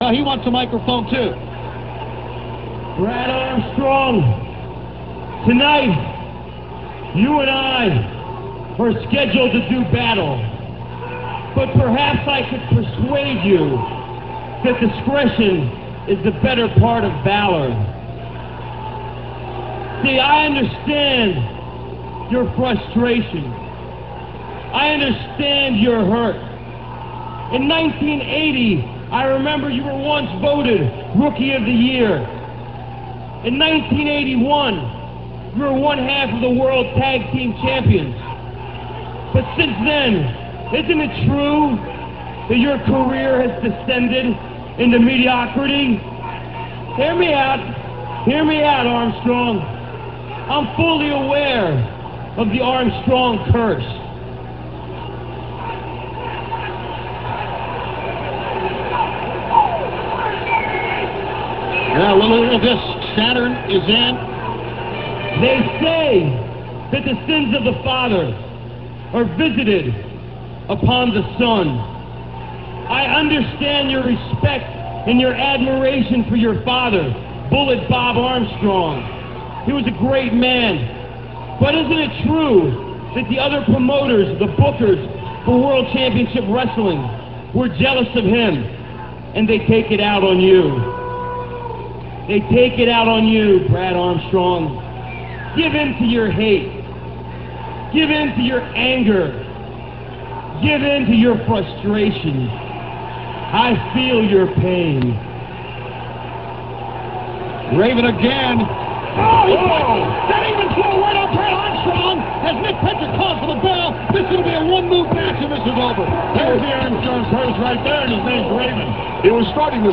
- This speech comes WCW Thunder - [3.12.98]. Raven suckers Brad Armstrong into thinking he can join the Flock, then DDT's him for the win.- (2:04)